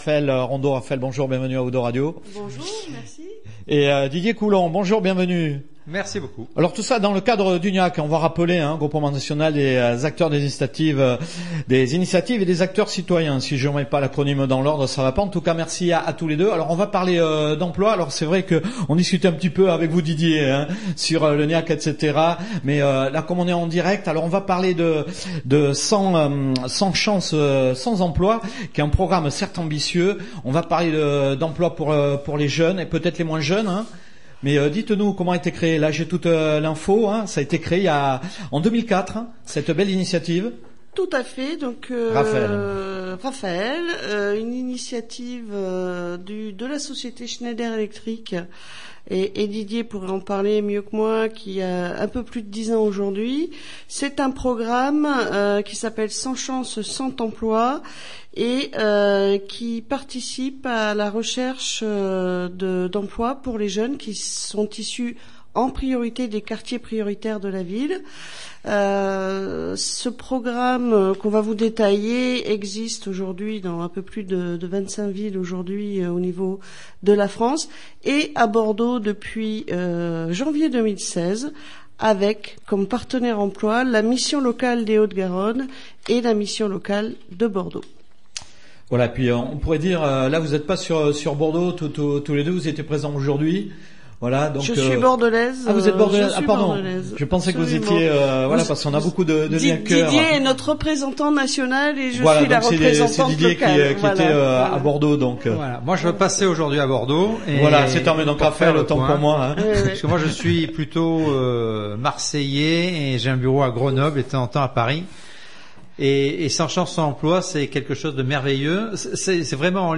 06/06/2017 : Interview GNIAC / 02 Radio : "100 Chances, 100 Emplois"